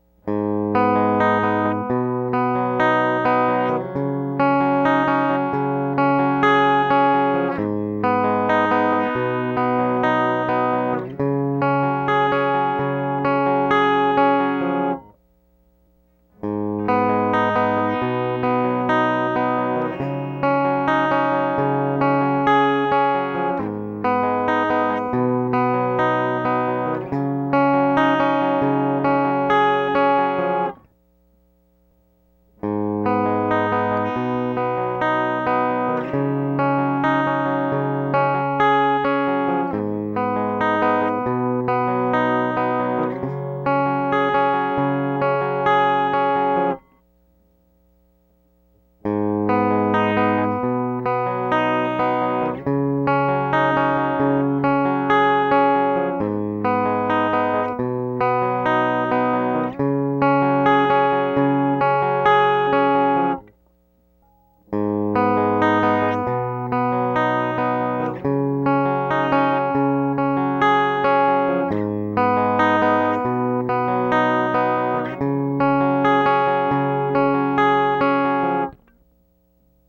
WAV file at 24/96, Ibanez Iceman 400->6FT Long Cable->MP-2->ProVLA->Adi8DS
Conquest - George L - Monster Bass - Monster Guitar - Mogami 2549
cables.wav